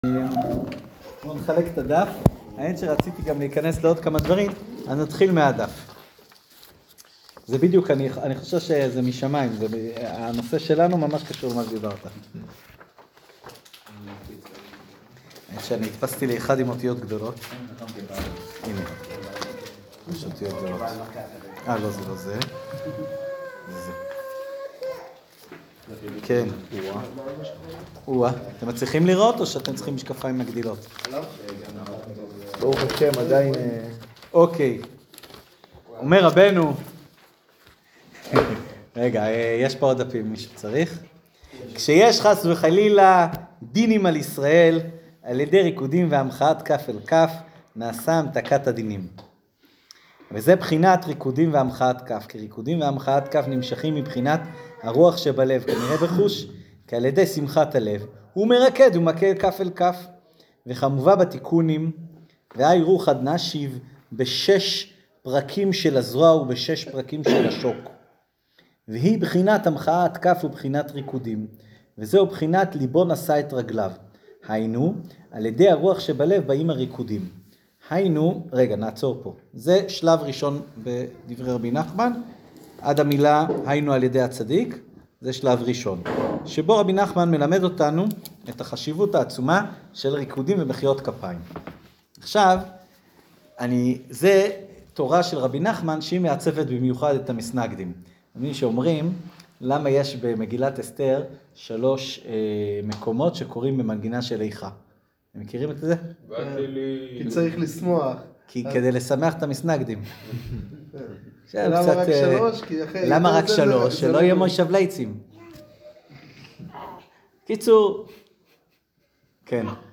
שיעור זוגיות